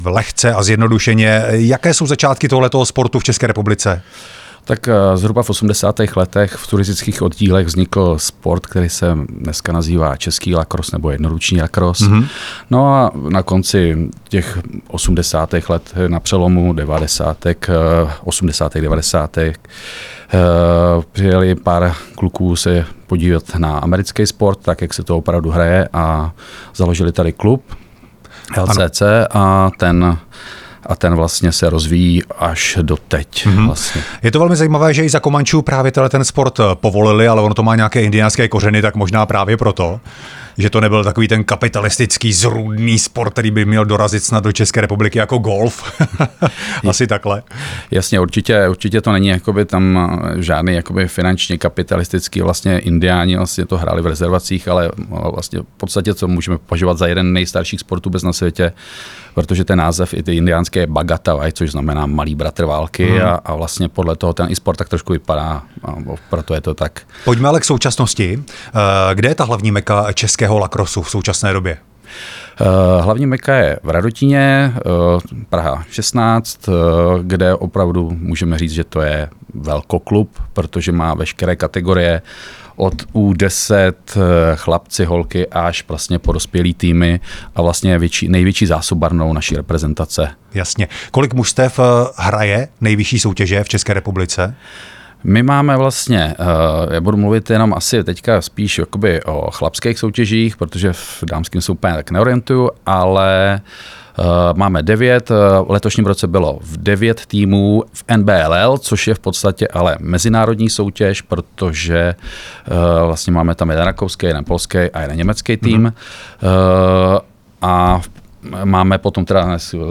Rozhovory
Moderátor